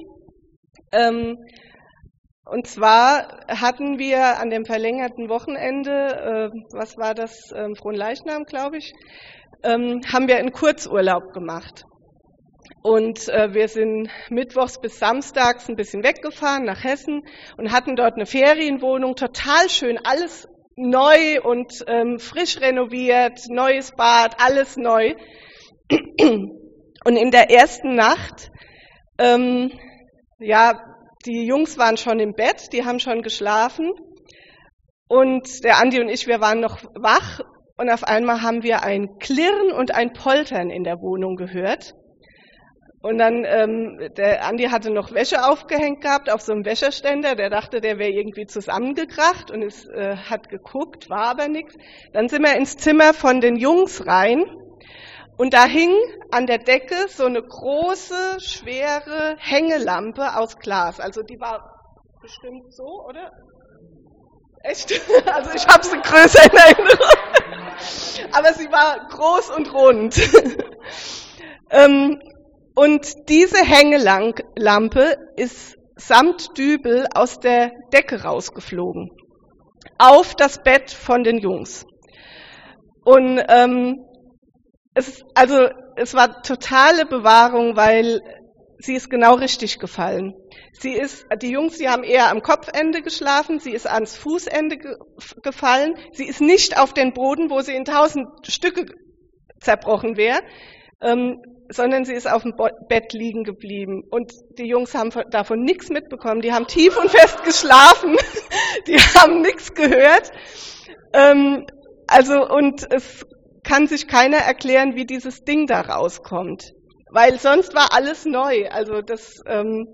Bibeltext zur Predigt: Epheser 6,10-18